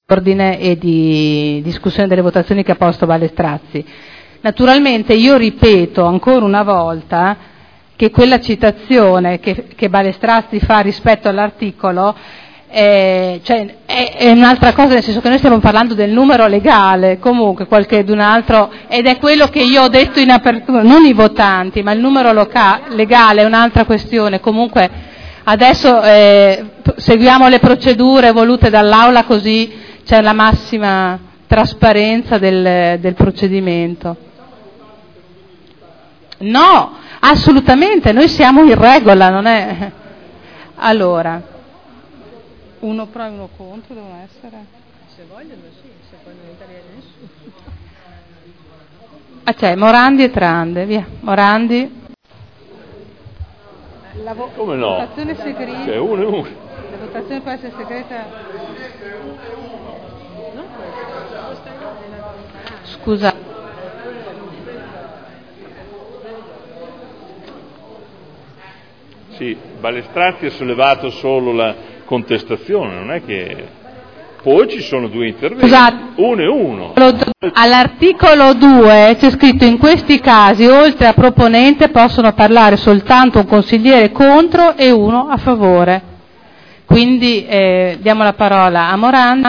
Seduta del 12/09/2011. Sulla mozione d'Ordine presentata dal Consigliere Ballestrazzi decide di dare la parola a un Consigliere a favore e ad un Consigliere contro.